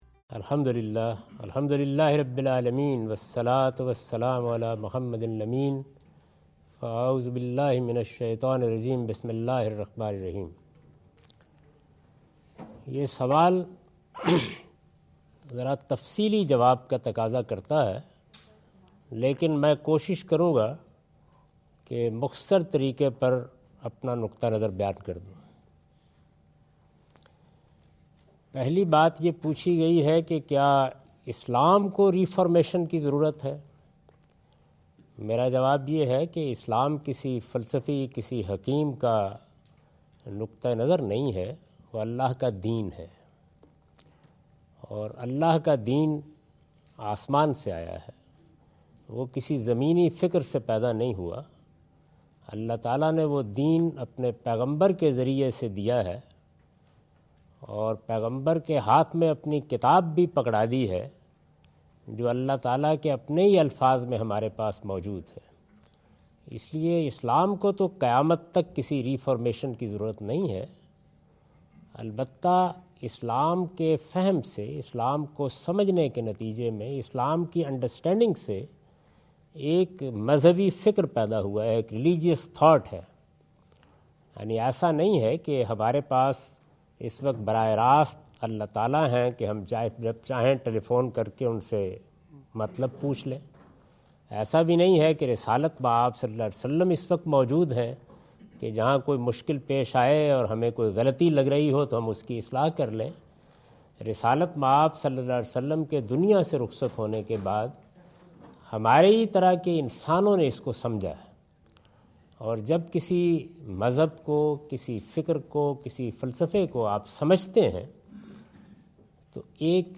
Q&A Session US Tour 2017, East-West University Chicago
In this video Javed Ahmad Ghamidi answers the questions asked at East-West University Chicago on September 24,2017.